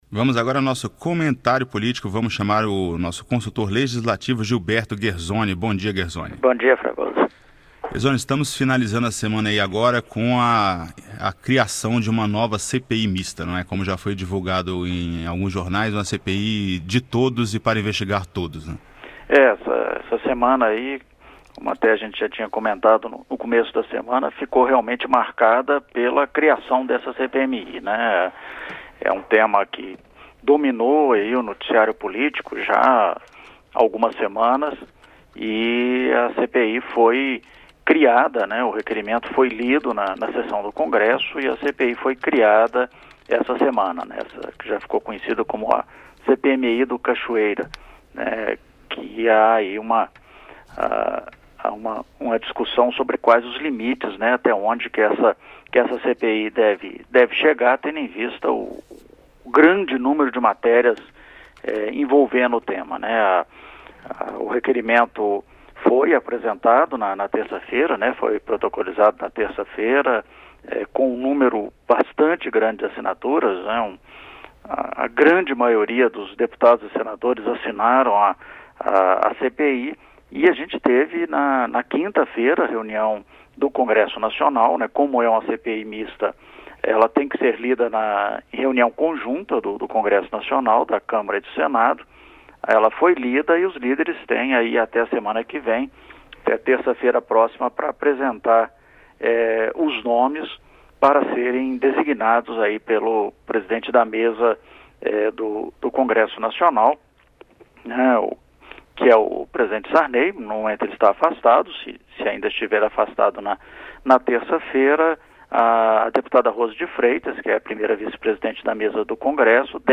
Comentário político